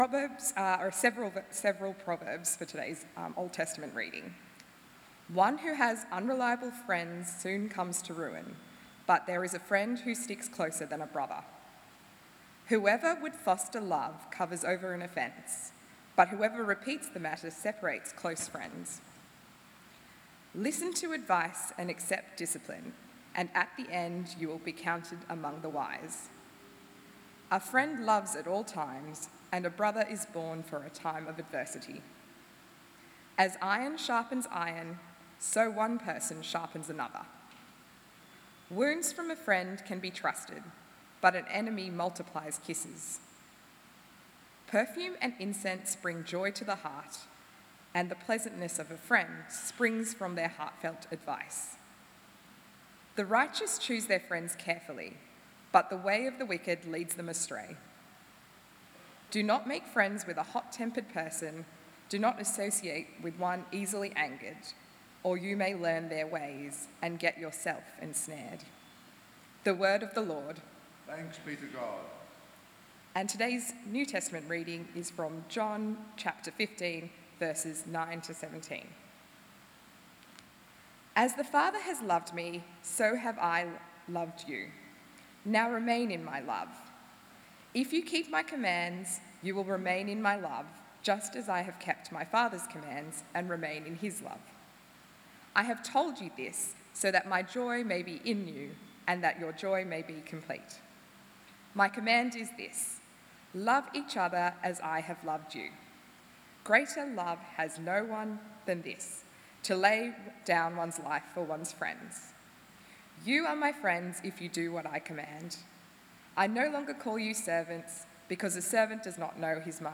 A sermon on friendship
delivered at St John's Darlinghurst in Sydney, Australia on 12 January 2025.